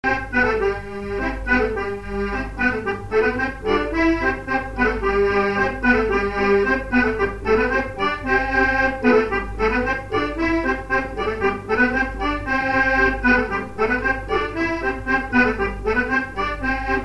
branle
Divertissements d'adultes - Couplets à danser
Pièce musicale inédite